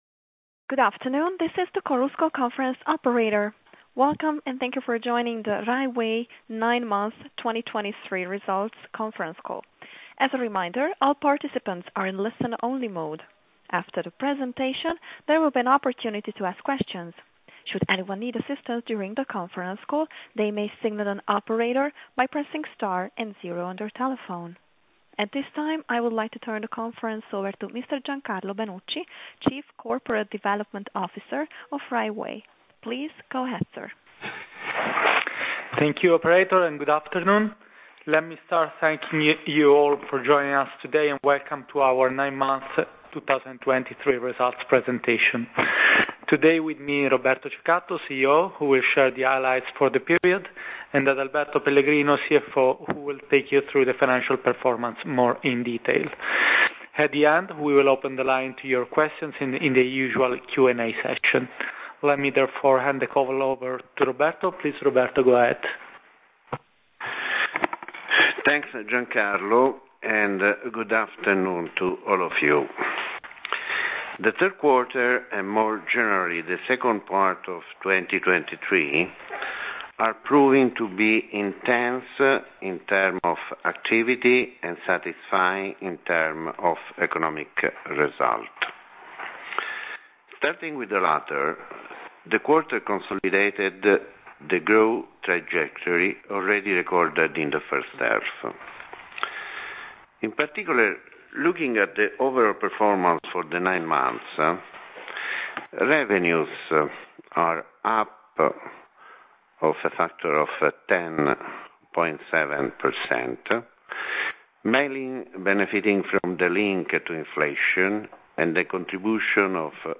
Audio mp3 Conference call Risultati 9M2023.mp3